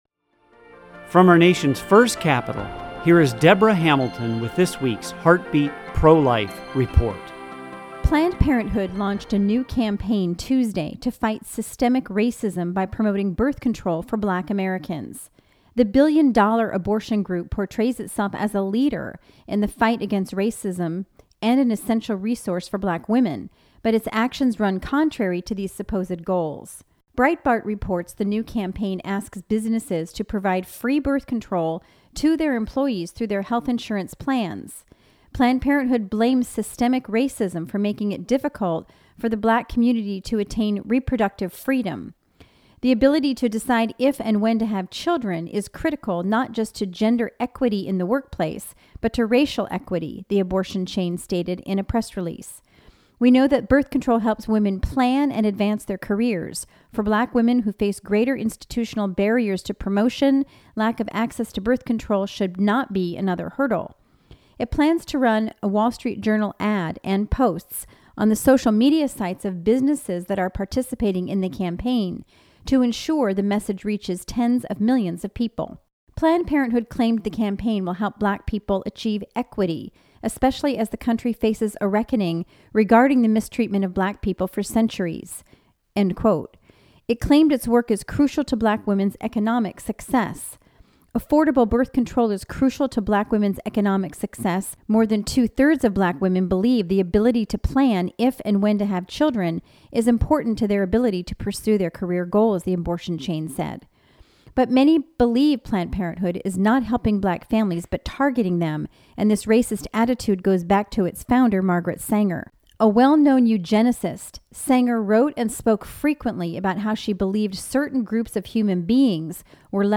Heartbeat Radio Feature: Week of June 29, 2020